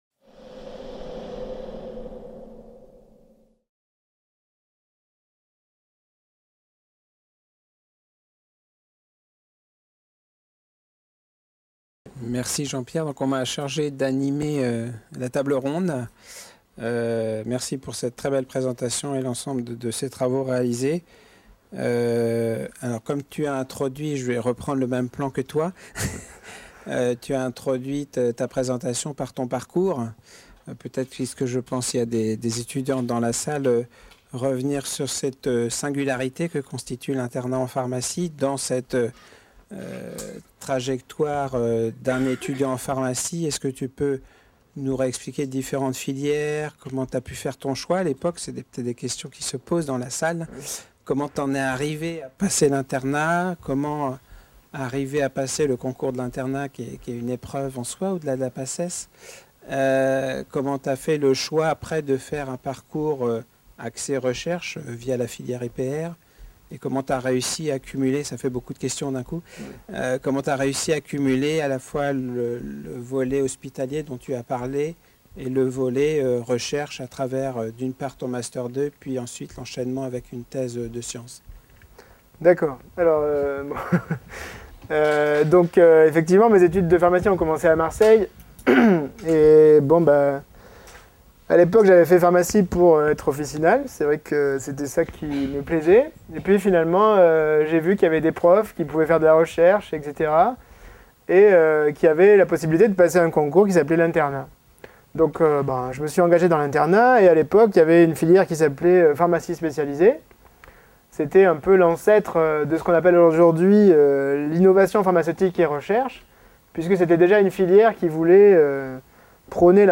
Les conférences santé de la BU